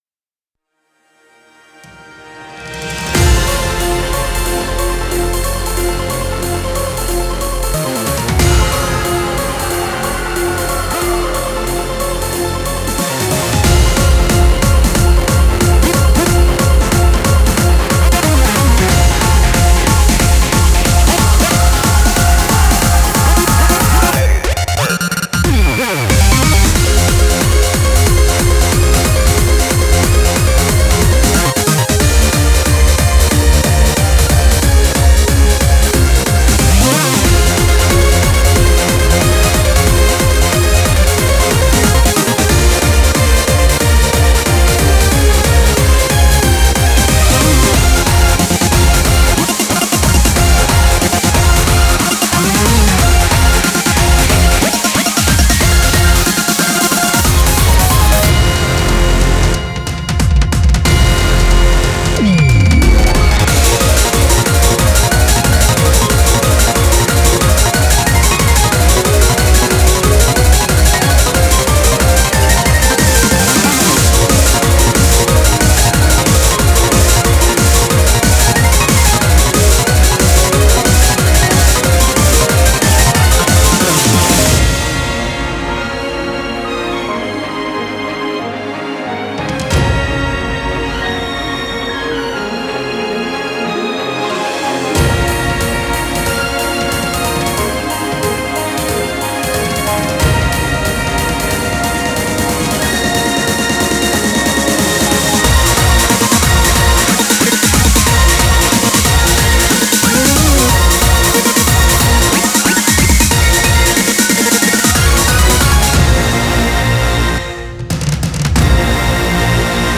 BPM92-183
Audio QualityPerfect (High Quality)
Genre: HARD SYMPHONIC.